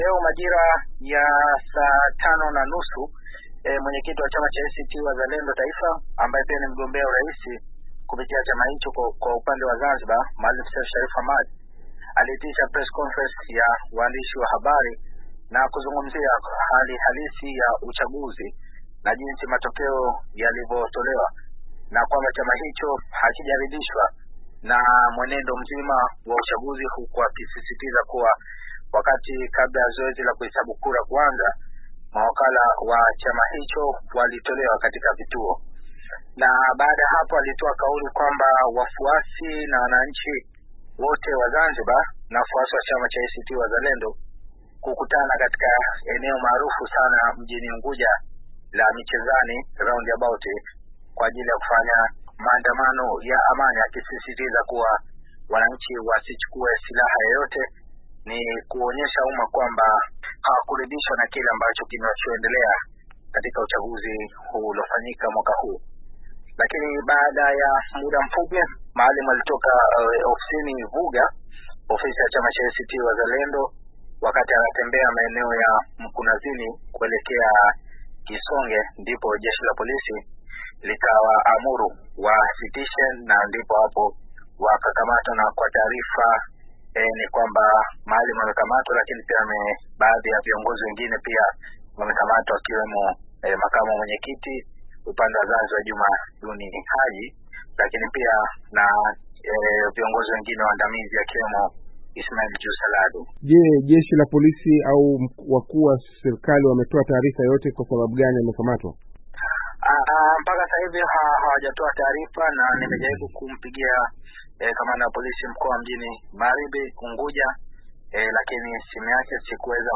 mwandishi habari azungumzia upinzani kuitisha maandamano